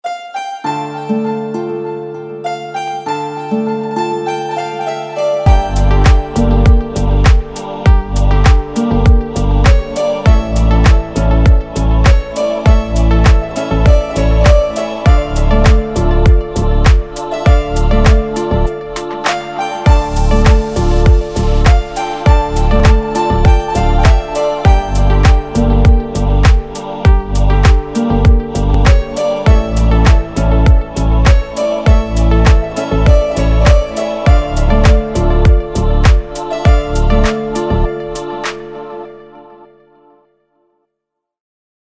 deep house
мелодичные , танцевальные